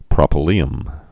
(prŏpə-lēəm, prōpə-)